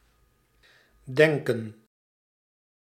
Ääntäminen
IPA: /ˈdɛŋkə(n)/